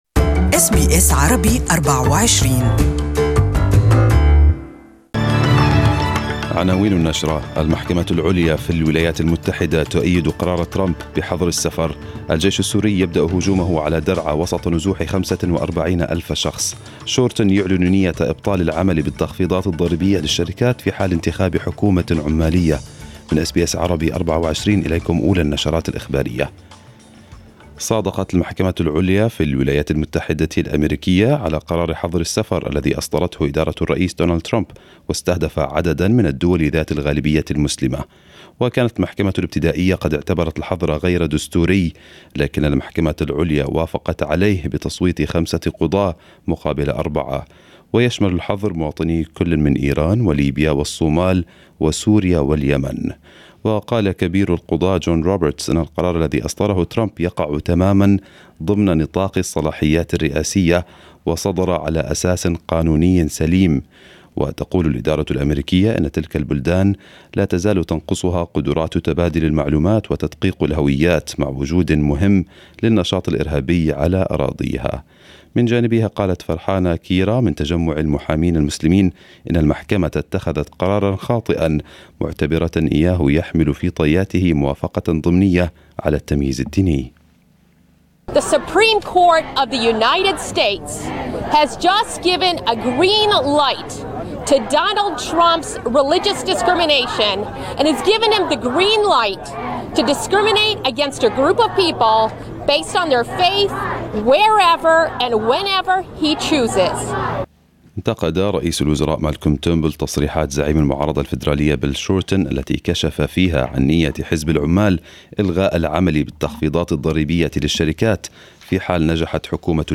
Arabic News Bulletin 27/06/2018